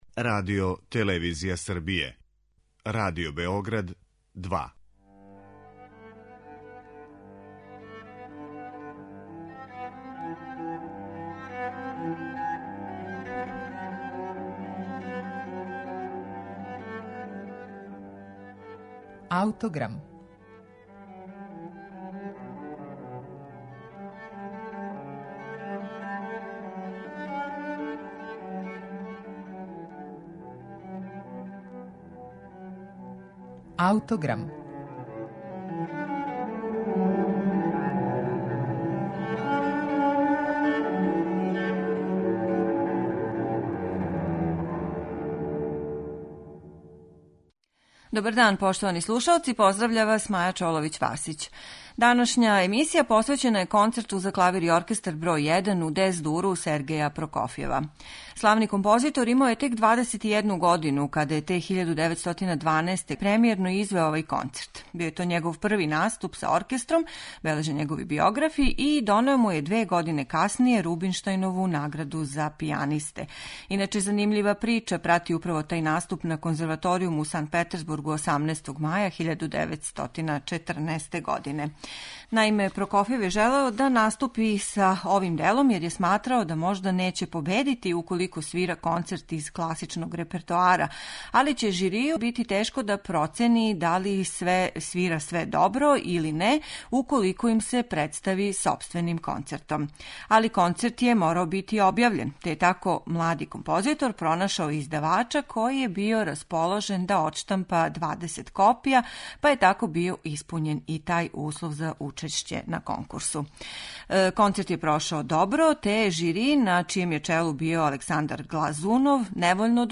Концерт поседује све одлике стила Сергеја Прокофјева: упечатљиве мелодије, пулсирајући ритам и несвакидашњи смисао за хумор. Слушаћете га у интерпретацији Александра Торадзеа и оркестра Маријинског театра, којим диригује Валериј Гергијев.